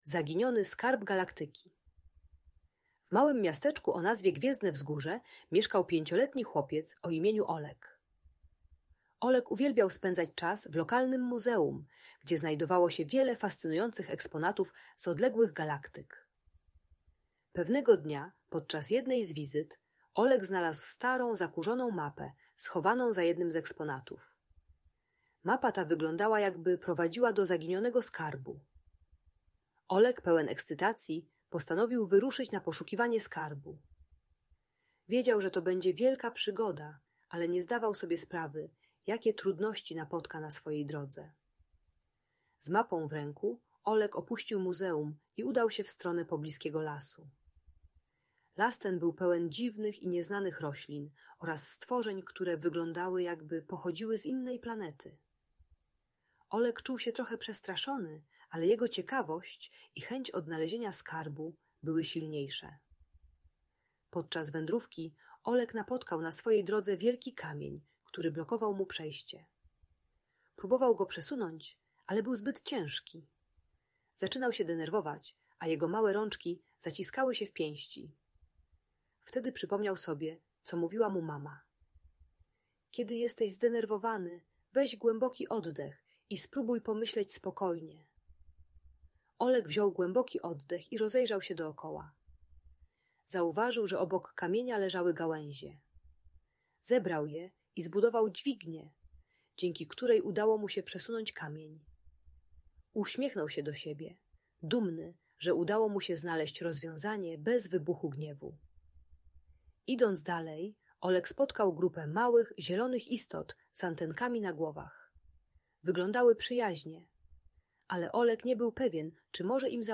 Galaktyczna przygoda Olka - Audiobajka